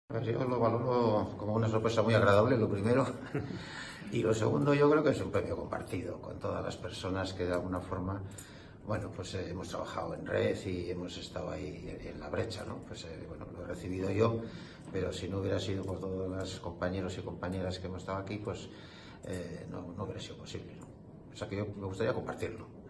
El auditorio Mitxelena, del Bizkaia Aretoa en Bilbao, sirvió de escenario para que la Organización agradeciera y homenajeara a toda la sociedad vasca por la solidaria actitud con la que día a día le apoya a través de la compra de sus productos de juego responsable.